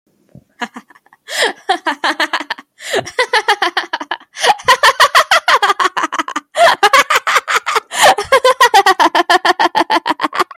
Laughing In A Mic Sound Button: Unblocked Meme Soundboard